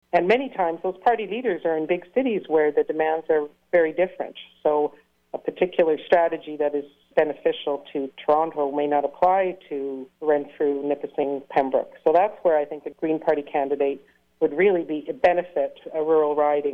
What you will be hearing is audio from an interview recorded May 22nd of 2022.